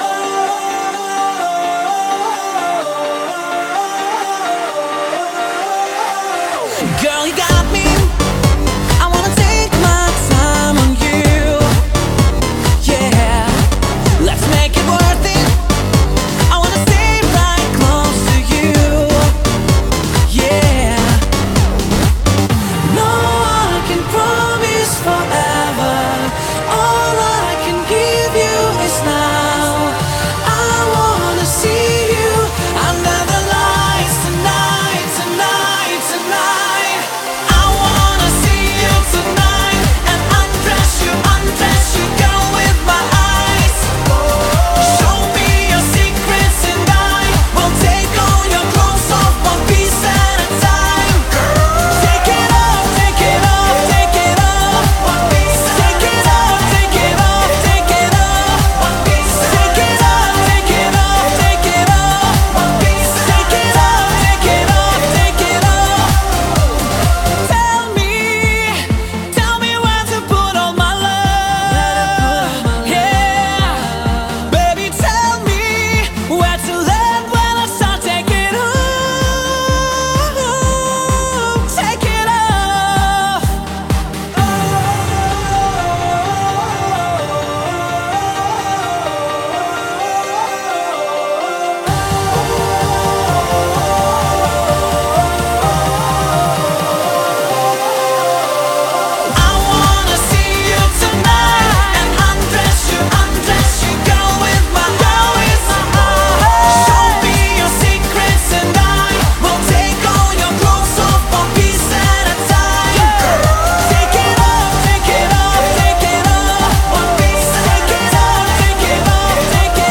BPM128
Audio QualityPerfect (High Quality)